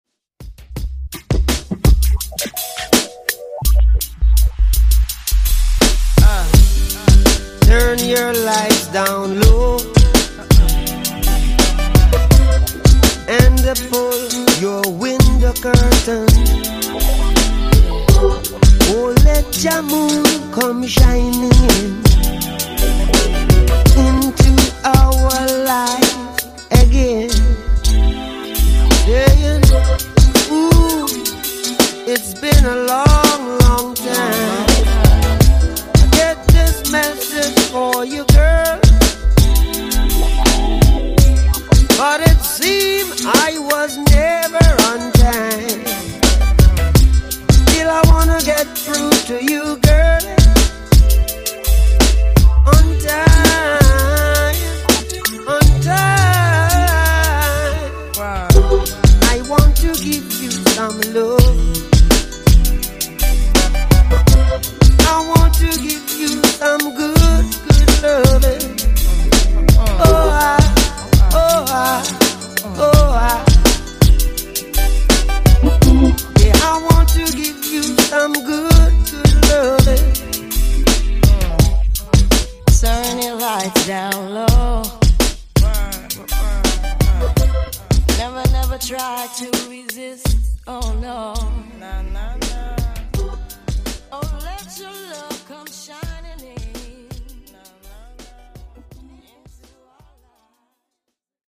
Genre: RE-DRUM
Clean BPM: 123 Time